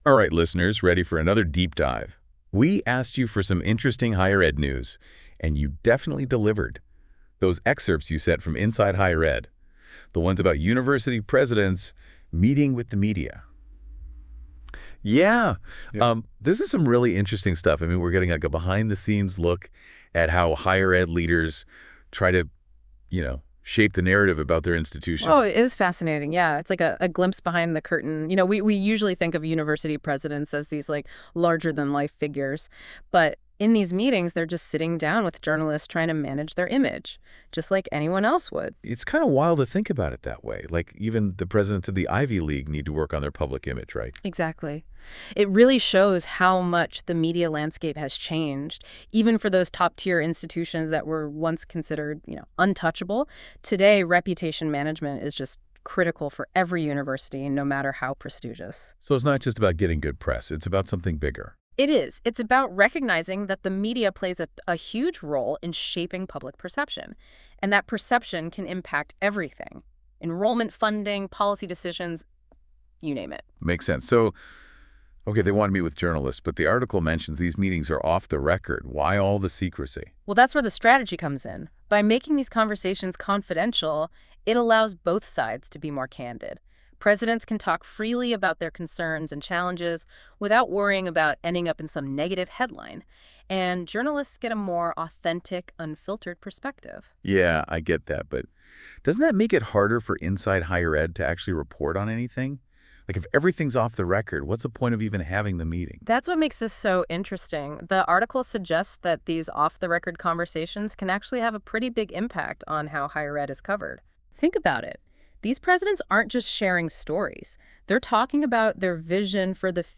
(I have seen the future and it is AI-generated.)